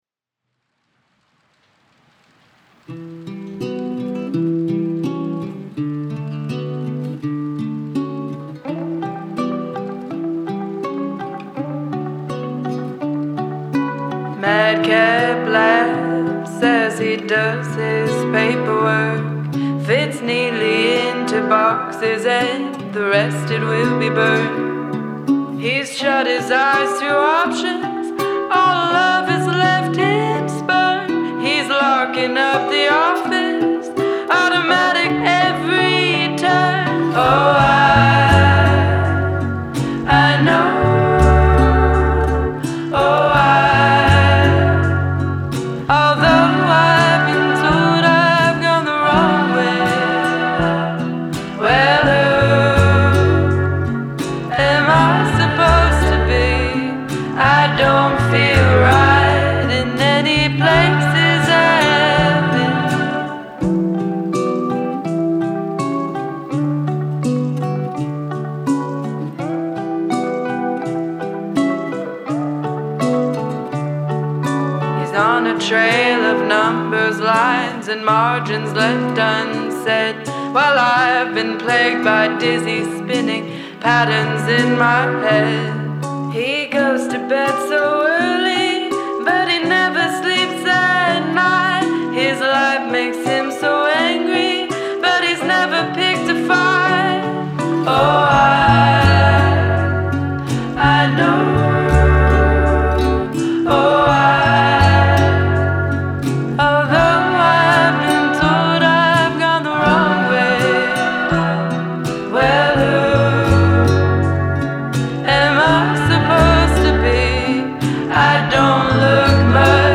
Singer Releases Single from Debut EP